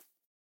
rabbit_hop2.ogg